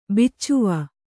♪ biccuva